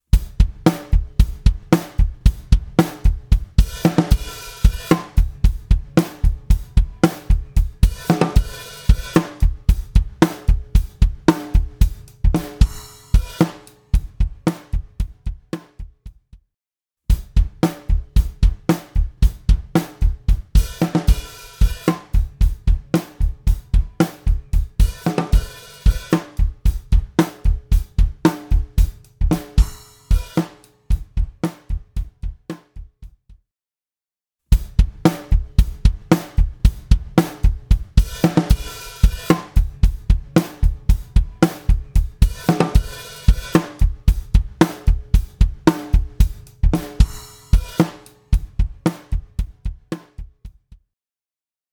Omnipressor | Drums | Preset: Squishy Room
サイドチェーンを備えたダイナミクス・エフェクト・プロセッサー
Omnipressor-Squishy-Room-Drum-Bus.mp3